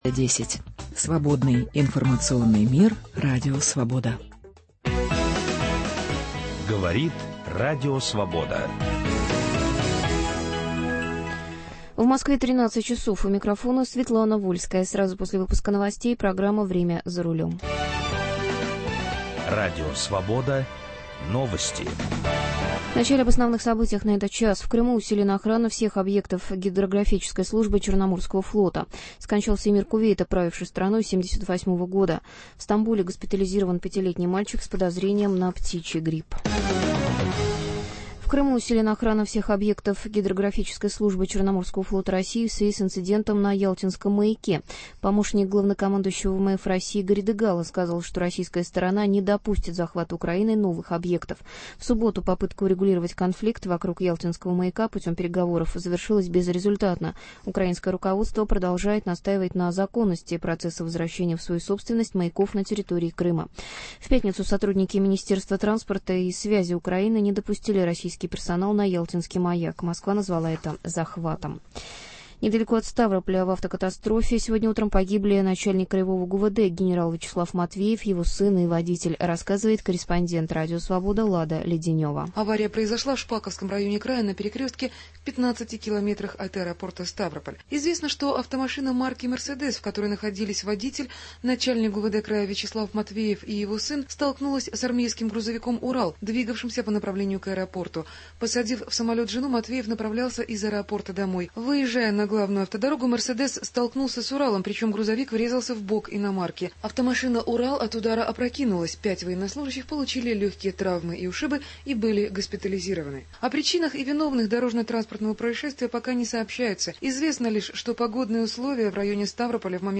По воскресеньям через раз в прямом эфире - из наших Московского и Екатеринбургского бюро.